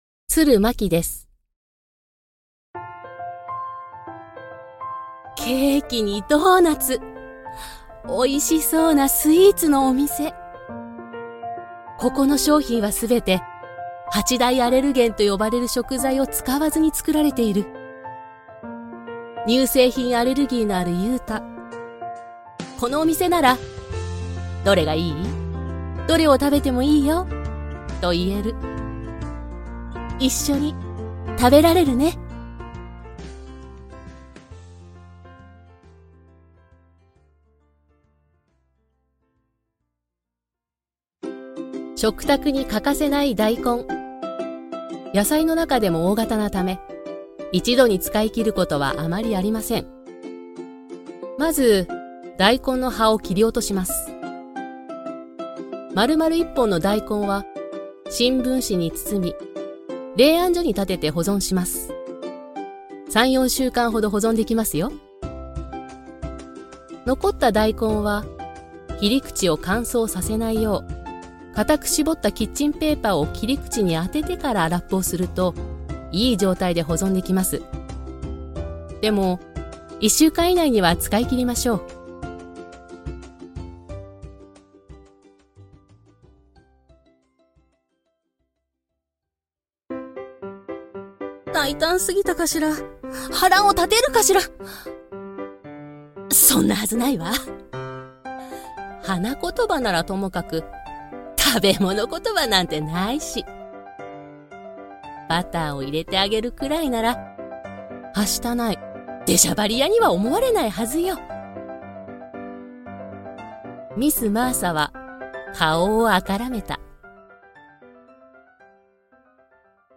明るく、落ち着いたナチュラルな声